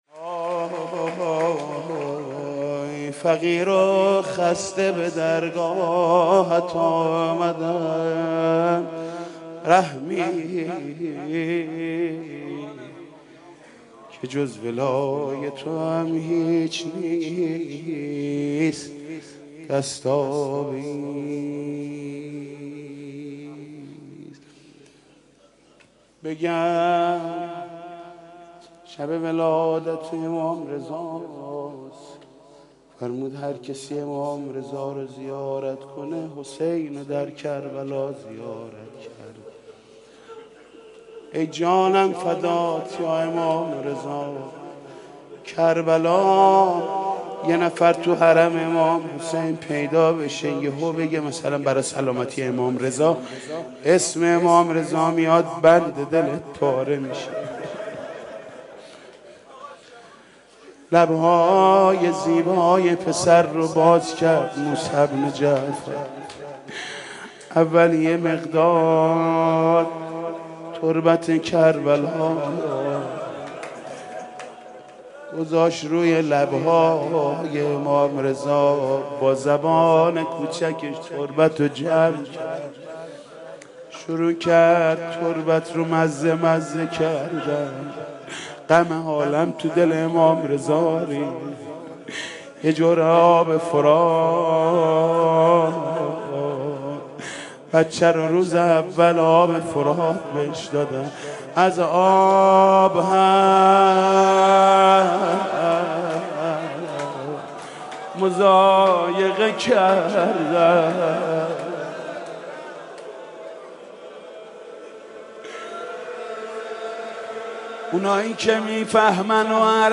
«میلاد امام رضا 1390» مدح: فقیر و خسته به درگاهت آمدم